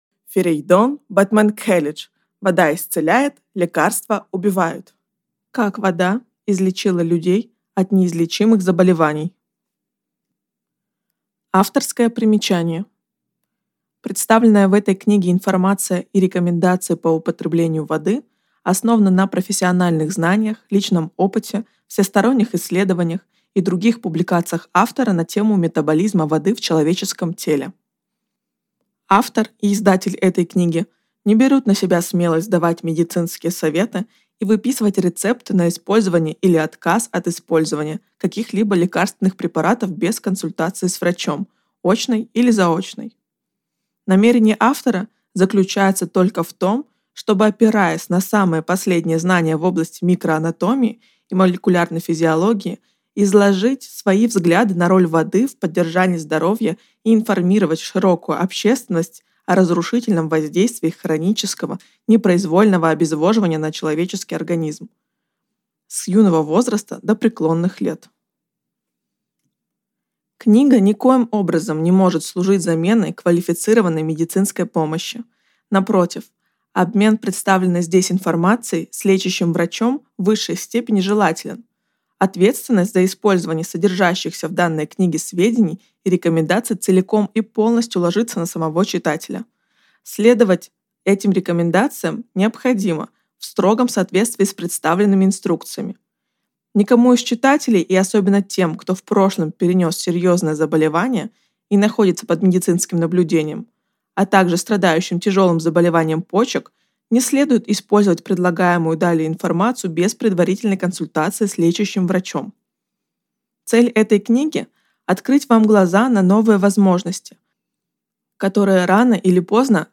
Аудиокнига Вода исцеляет, лекарства убивают | Библиотека аудиокниг